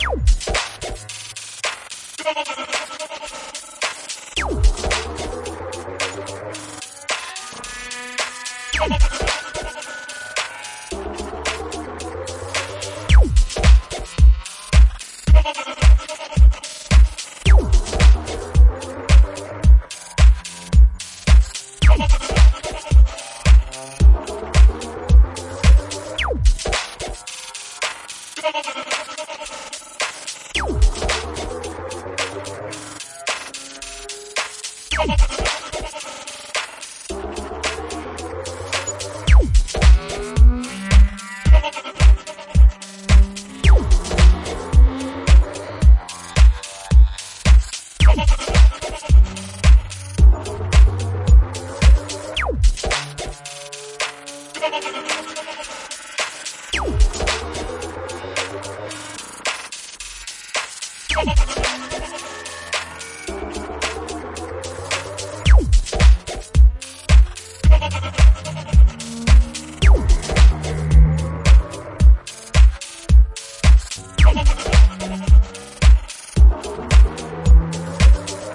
节奏 节拍 457409 循环 pareidolia 低音
声道立体声